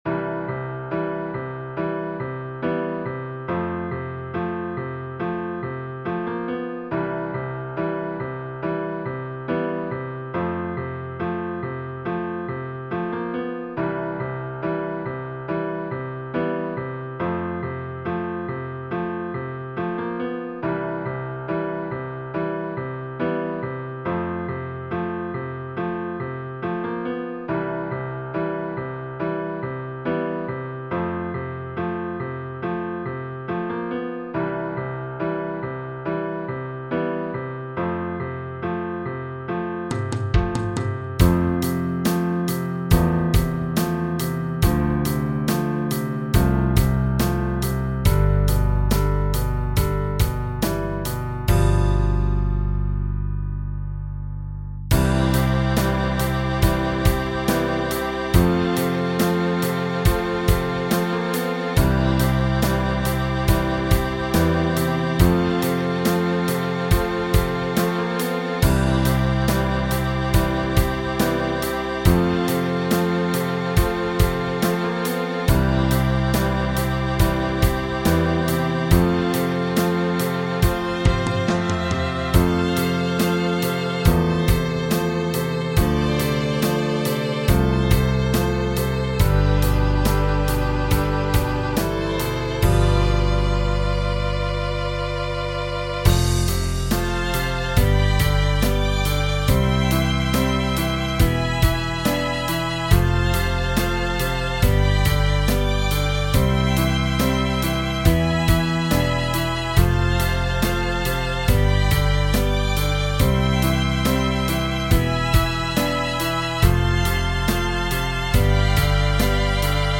Übungsaufnahmen
Chorprojekt 2020